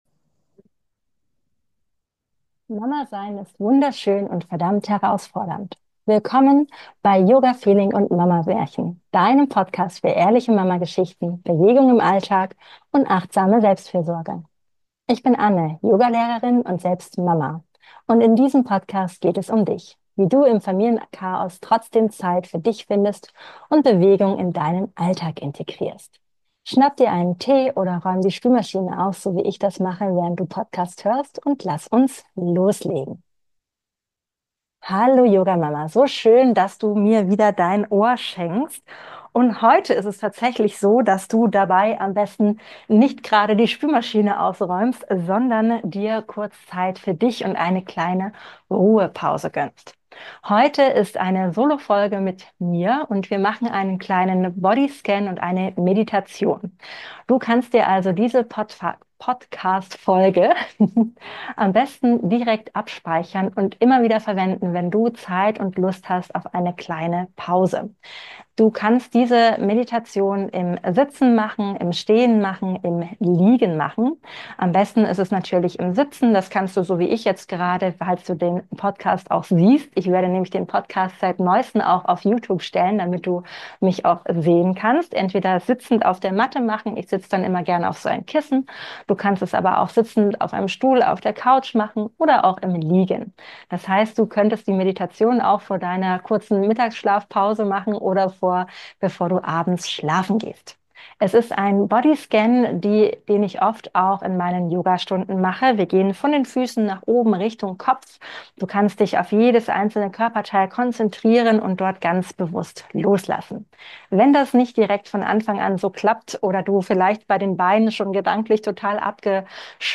In dieser kurzen, aber wirkungsvollen Podcastfolge lade ich dich zu einer ganz besonderen Auszeit ein. Gönn dir einen Moment der Ruhe mit einer Meditation und einer Traumreise – genau so, wie ich sie am Ende meiner Yogastunden für uns Mamas mache.